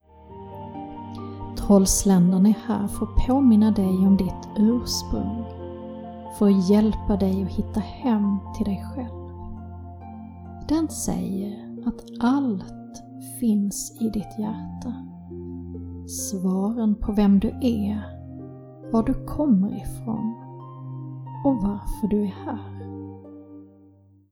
Öppna ditt hjärta – guidad meditation, 19 min
Musik: ”Dance of Life” av Peder B Helland, Soothing Relaxation AS